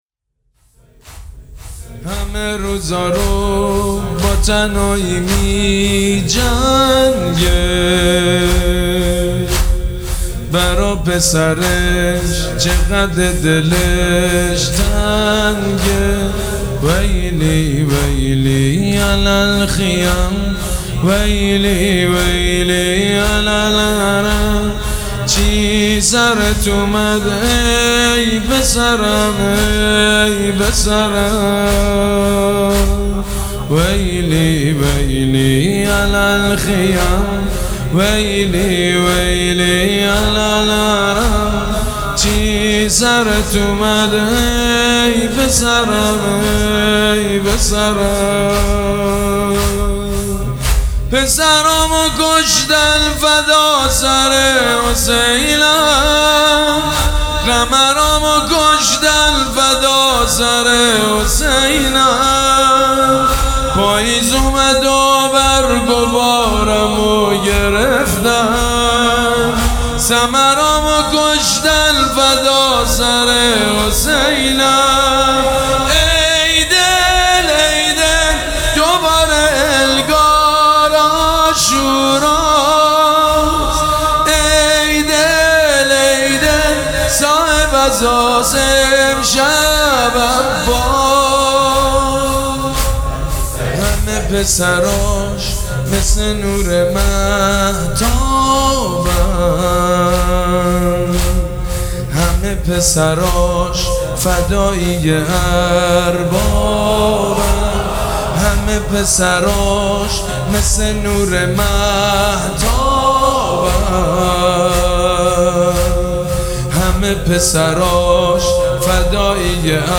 مداح
وفات حضرت ام البنین (س)